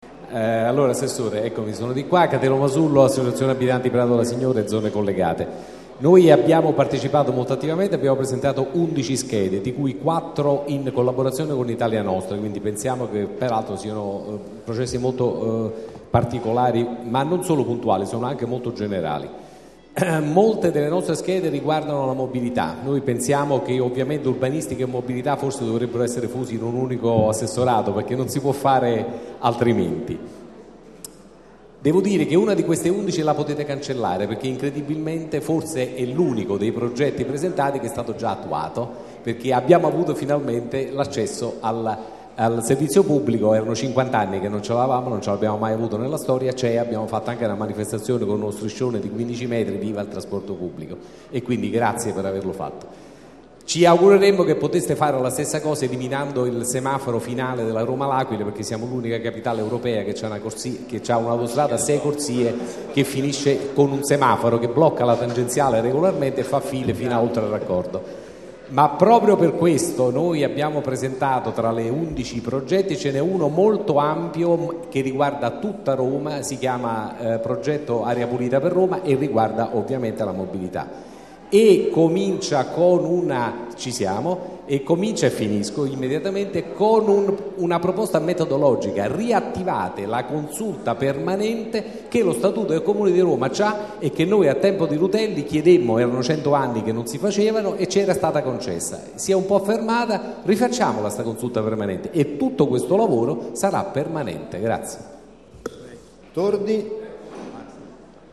Registrazione integrale dell'incontro svoltosi il 19 dicembre 2014 presso la Casa della Città, in P.za Da Verrazzano, 7.